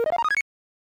deltarune egg sound Meme Sound Effect
deltarune egg sound.mp3